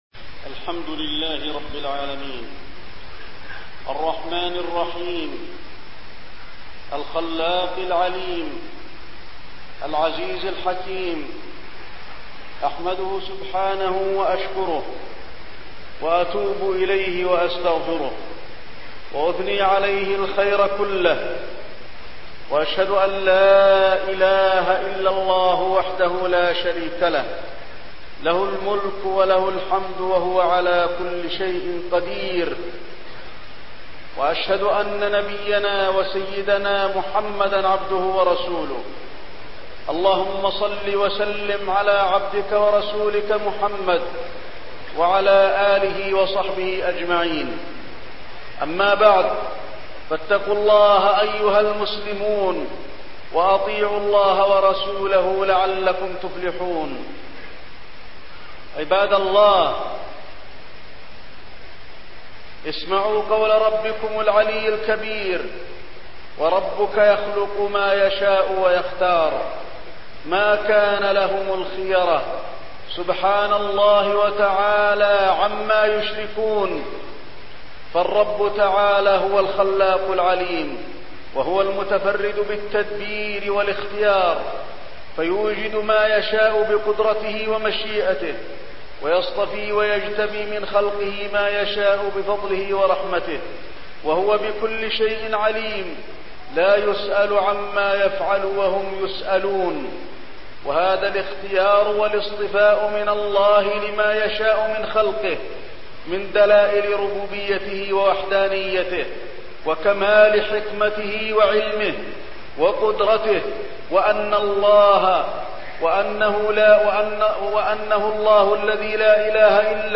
خطبة فضل عشر ذي الحجة 1 الشيخ علي بن عبد الرحمن الحذيفي
خطب الجمعة والأعياد